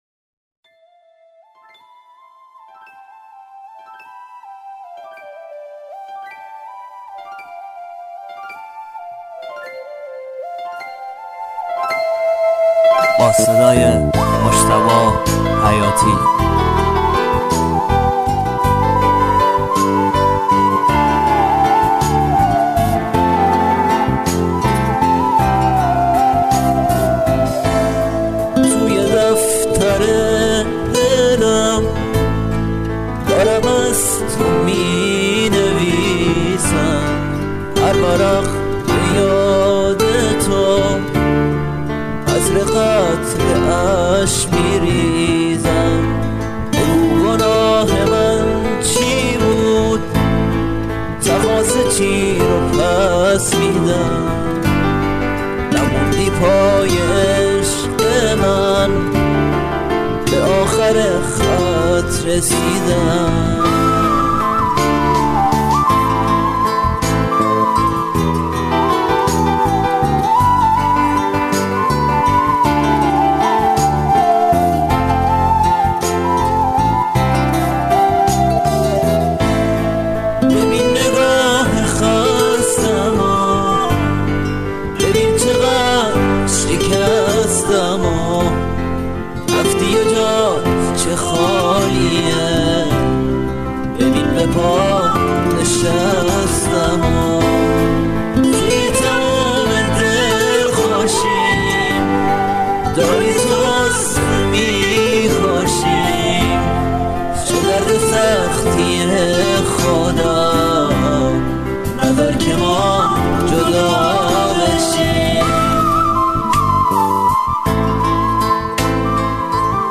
دسته بندي : تک آهنگ ,